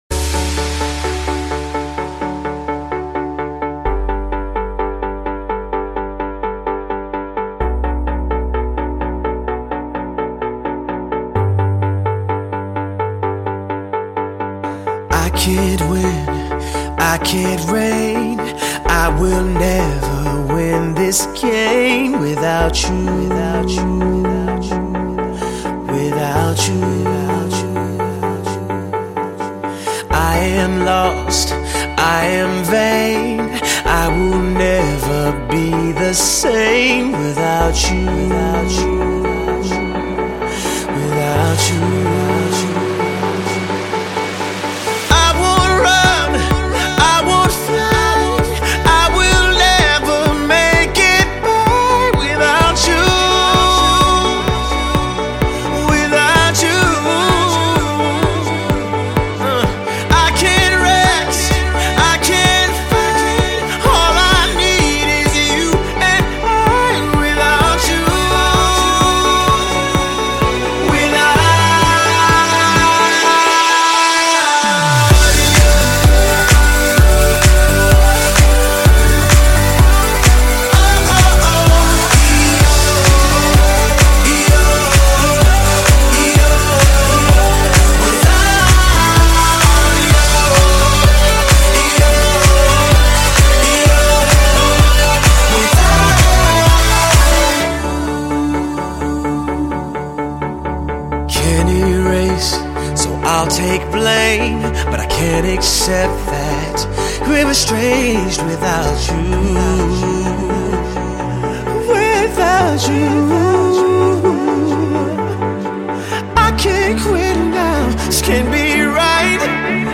Главная » Файлы » Trance , Disco, Club , D&J ,